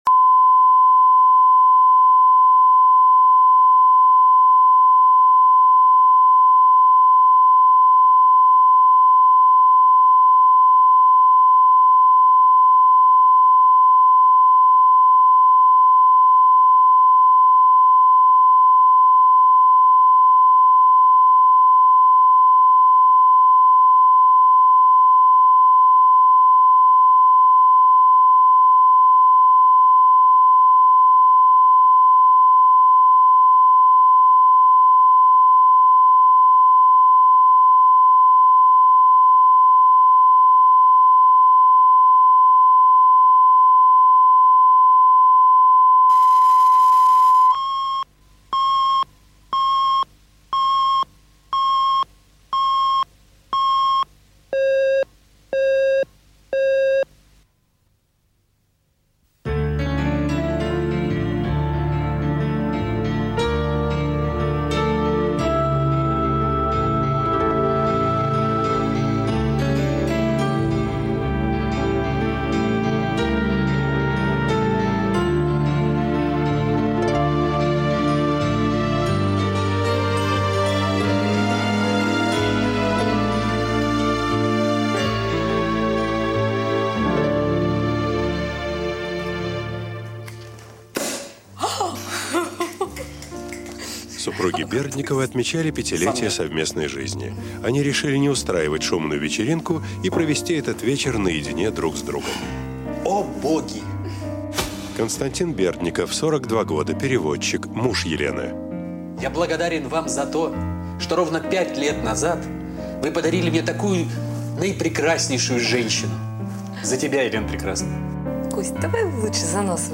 Аудиокнига Подарок | Библиотека аудиокниг
Прослушать и бесплатно скачать фрагмент аудиокниги